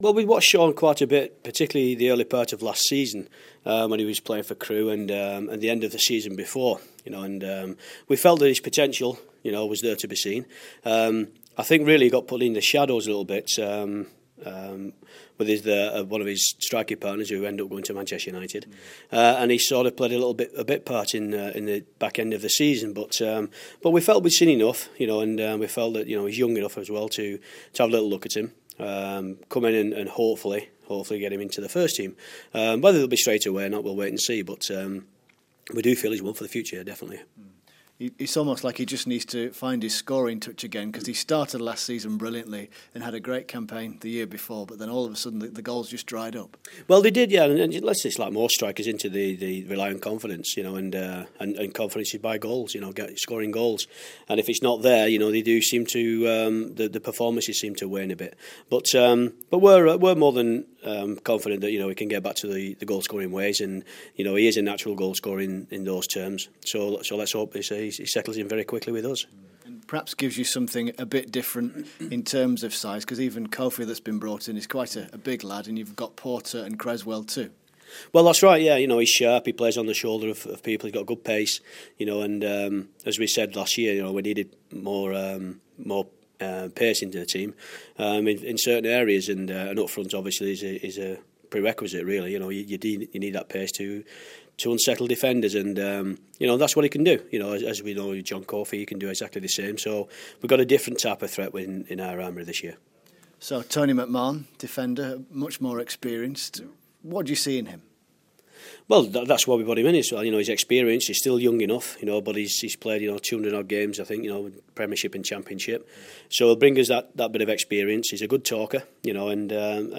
Sheffield United manager Danny Wilson talks about his 3 new signings.